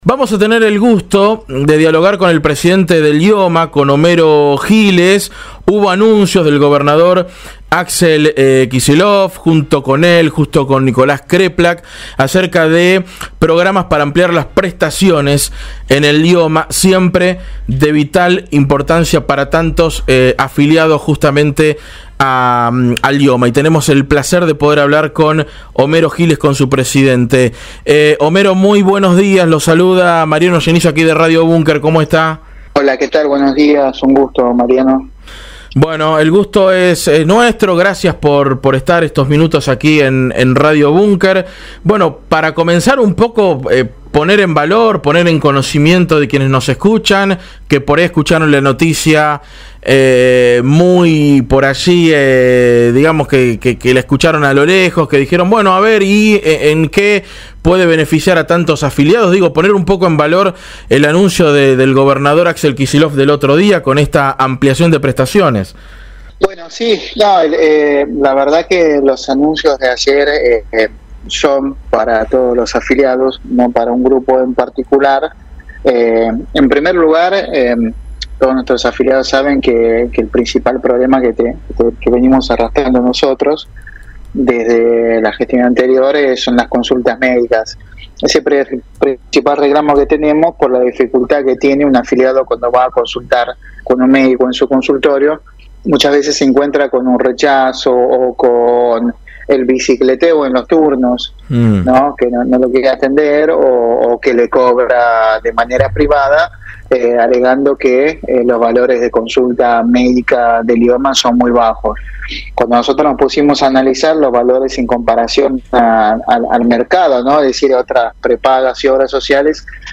El Presidente de IOMA Homero Giles mantuvo comunicación con Radio Bunker en el programa el “Tanke de Bunker” y se refirió a las medidas que anunció el gobernador Axel Kicillof el lunes pasado acerca del nuevo programa de medicamentos esenciales, la apertura de la afiliación voluntaria a personas entre 18 y 35 años y el aumento en honorarios médicos.